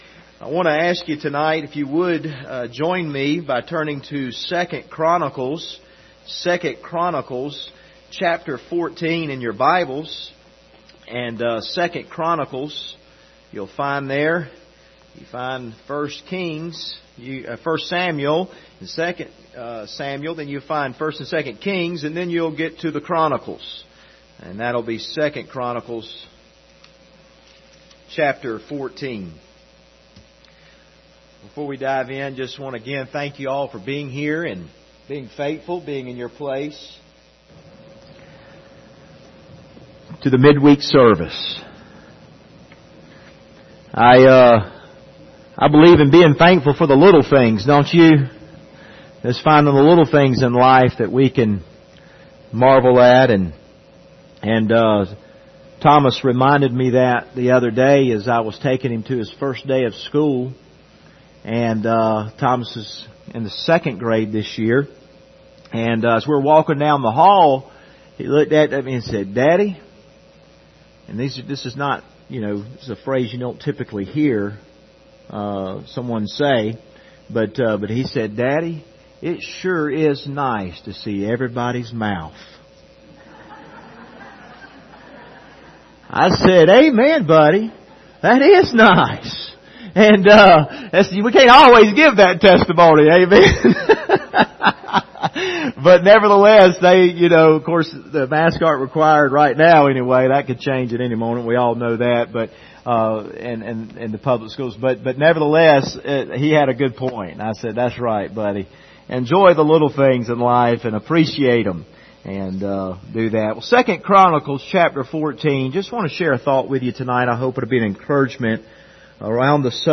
2 Chronicles 14:1-11 Service Type: Wednesday Evening Topics: faith , prayer « The Lord Hath Need of Thee Jehovah Shalom